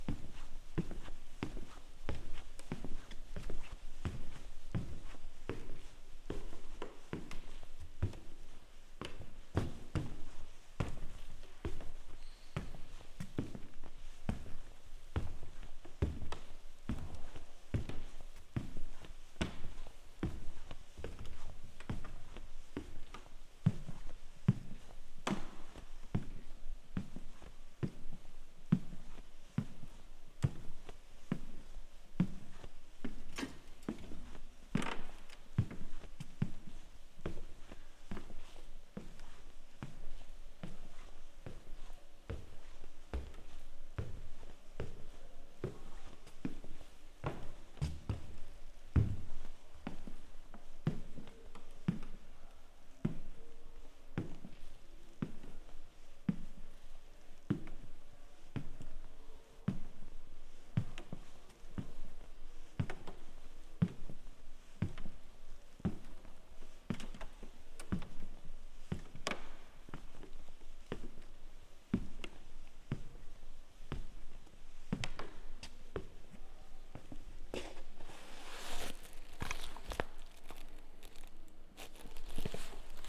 발걸음01.mp3